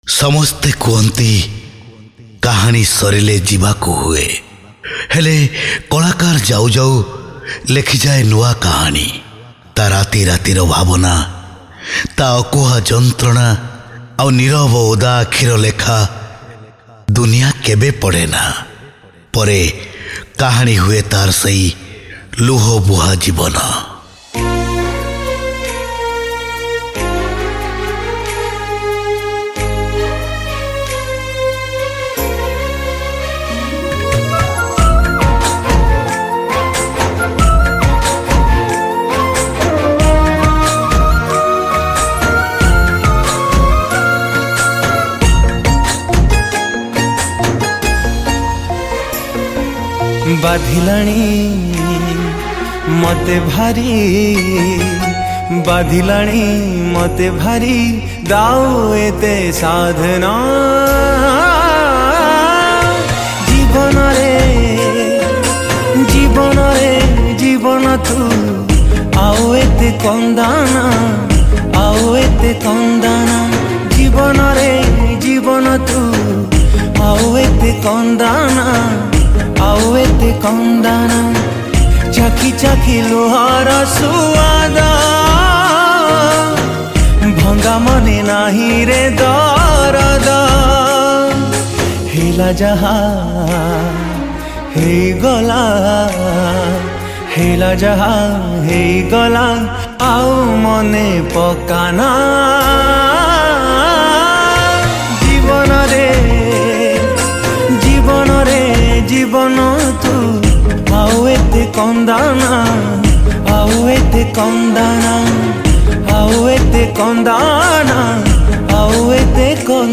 (Studio Version)